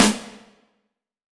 SNARE 100.wav